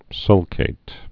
(sŭlkāt)